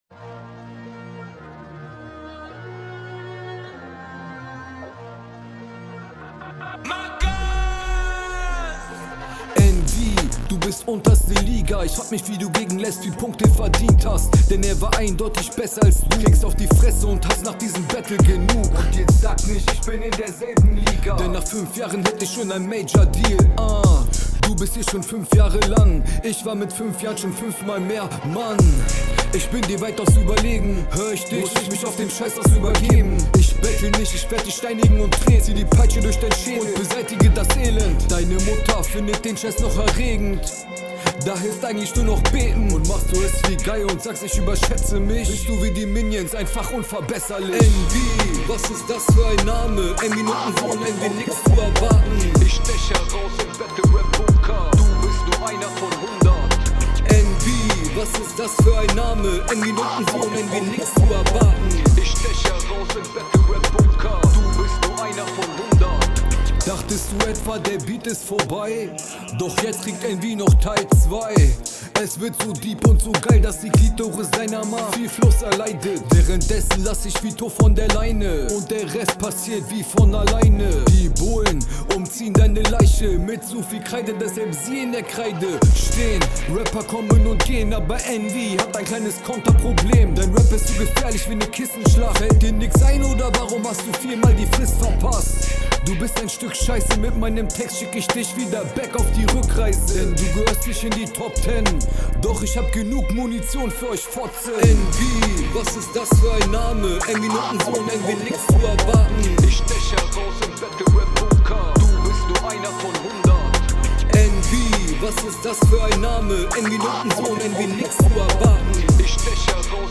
Mix geht noch was.